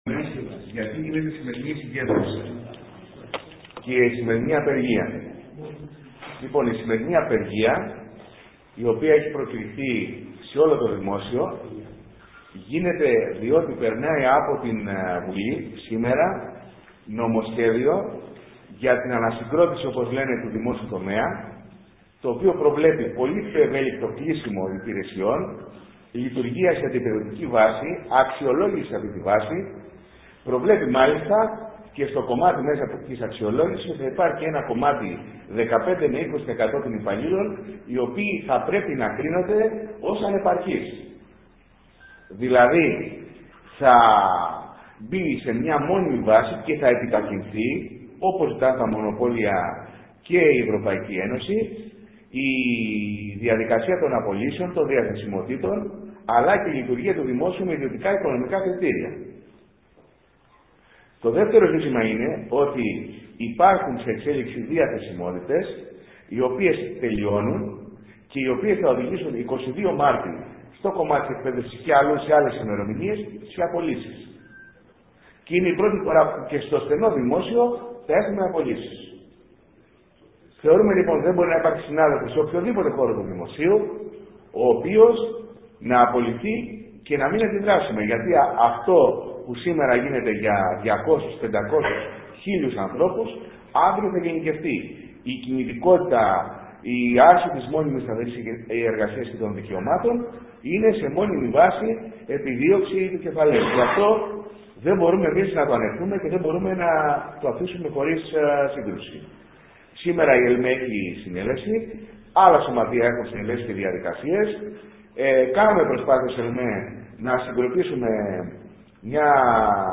ΟΜΙΛΙΕΣ
ΟΜΙΛΙΕΣ.mp3